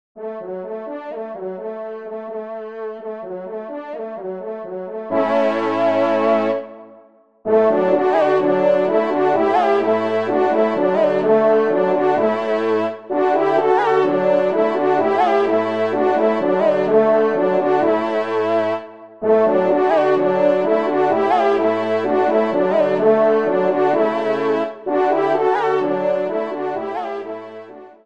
TON DE VÈNERIE   :
ENSEMBLE